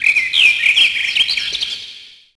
BirdSanctuary.wav